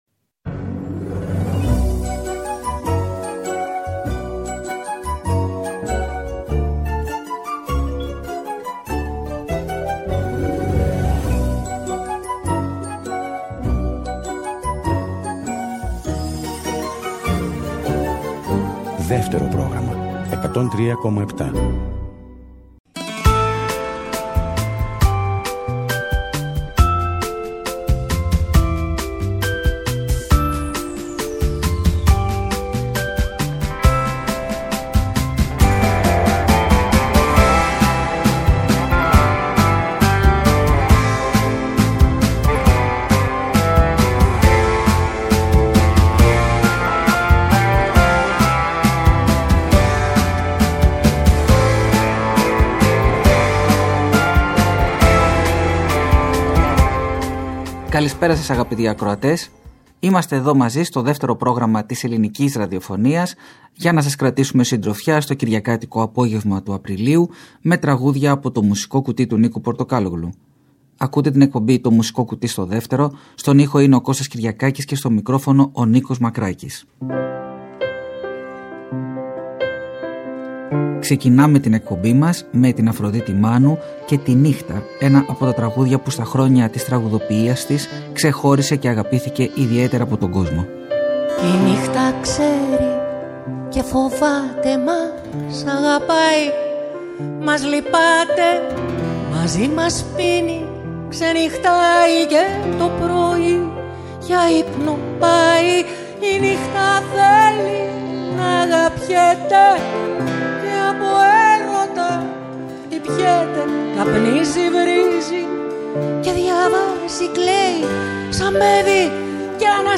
επιλεγεί τραγούδια που ακούστηκαν στο τηλεοπτικό «Μουσικό Κουτί»